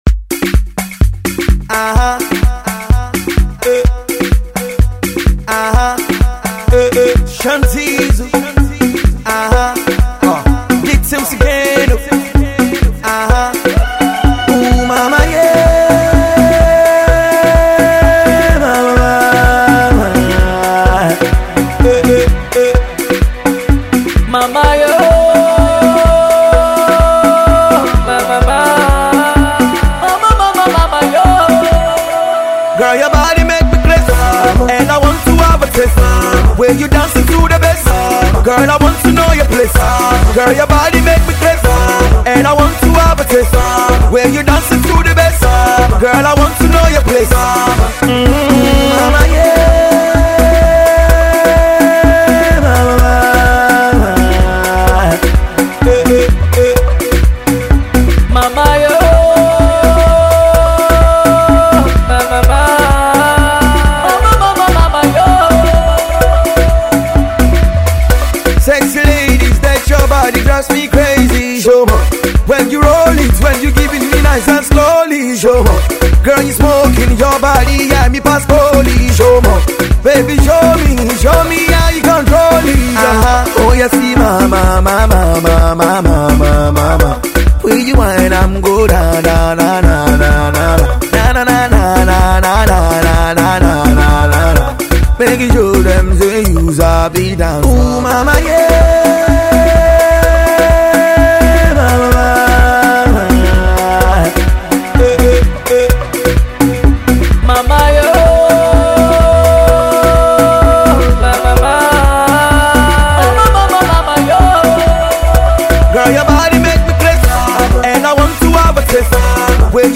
has a very fun South African Techno feel